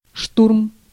Ääntäminen
US : IPA : [briːtʃ]